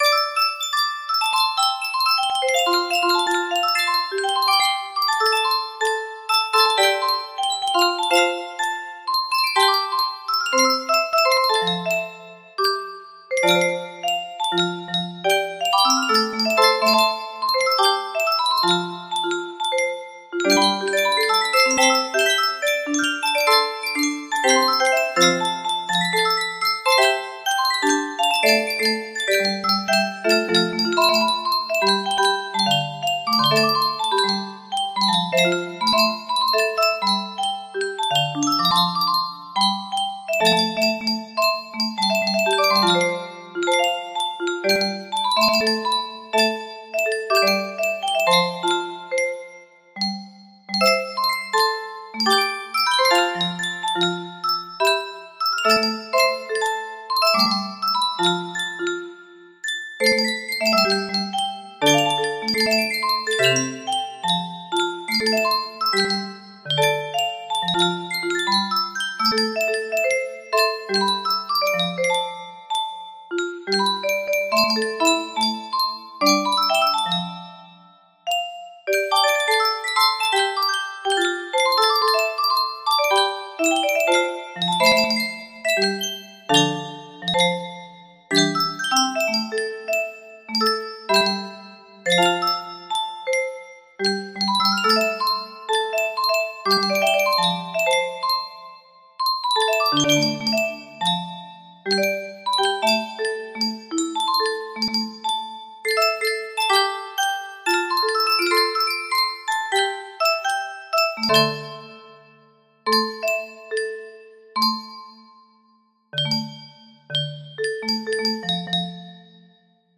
I'm Falling Down A Constellation of Stars music box melody
Full range 60